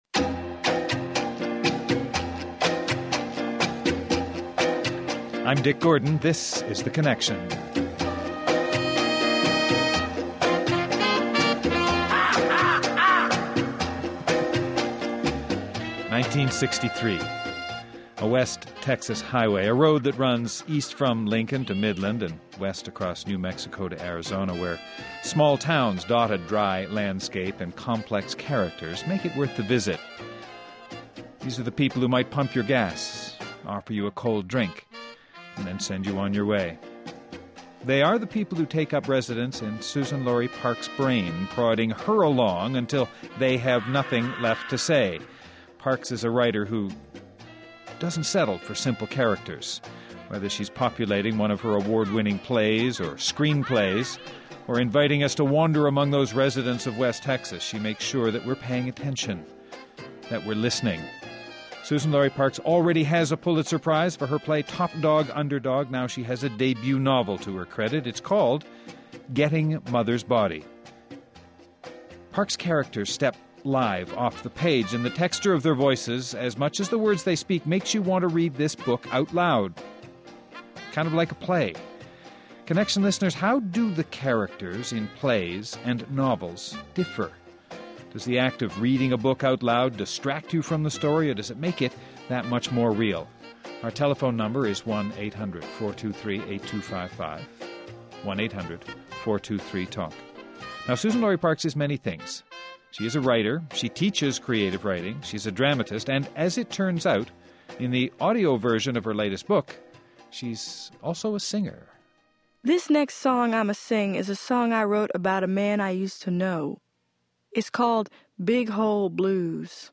Guests: Katrina vanden Heuvel, editor, The Nation Bill Emmott, editor, The Economist